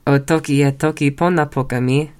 Note: the stress in toki pona falls on the first syllable of words. In some of the recordings it is misplaced.